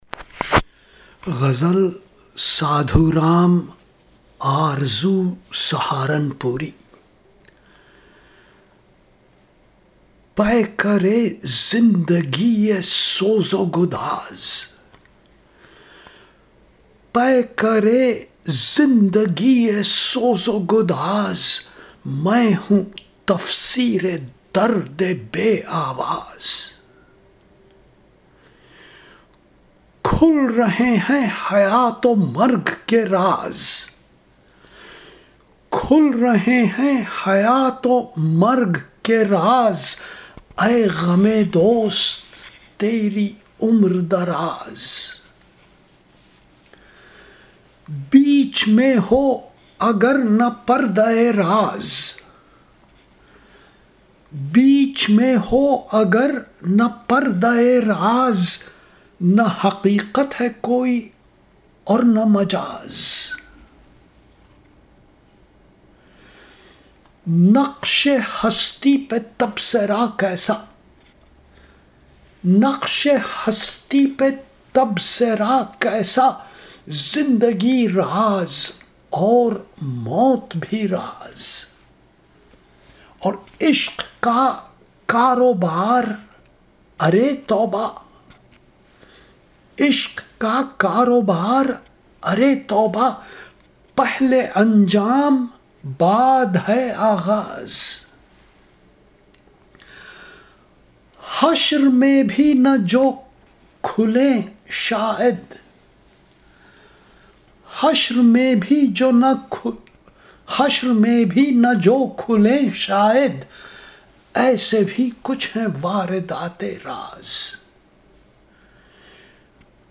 Recitation